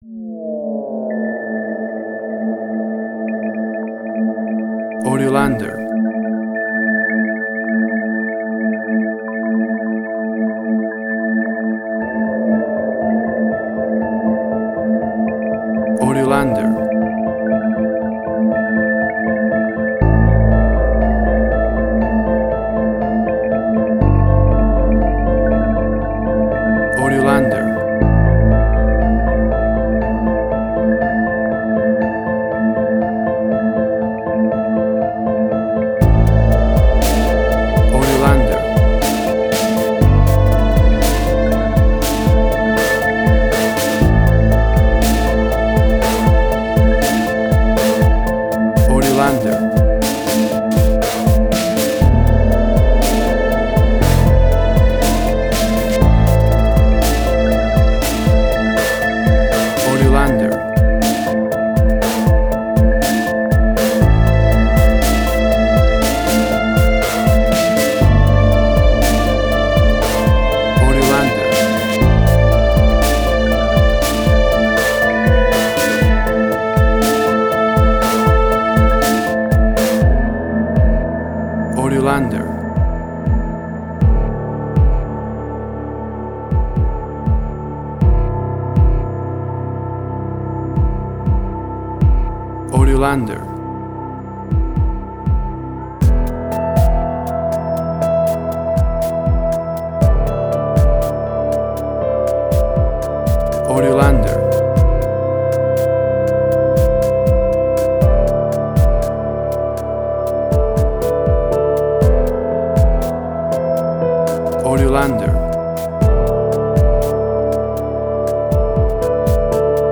Suspense, Drama, Quirky, Emotional.
Tempo (BPM): 120